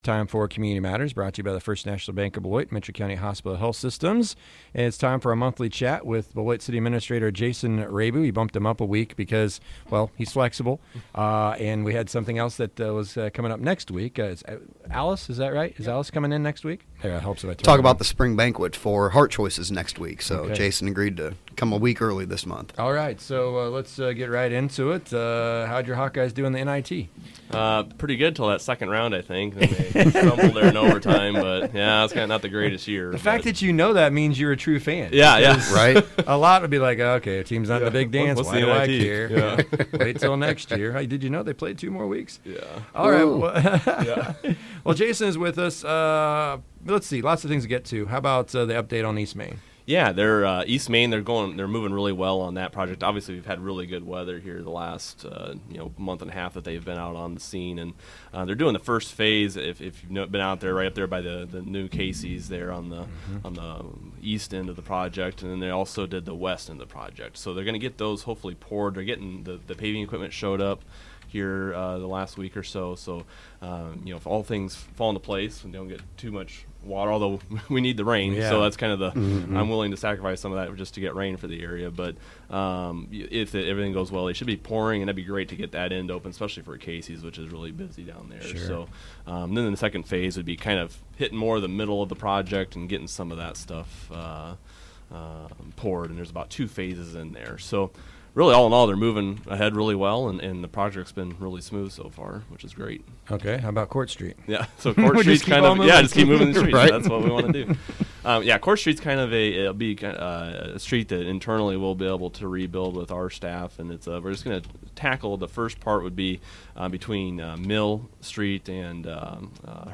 Beloit City Administrator Jason Rabe joins us to discuss current city projects.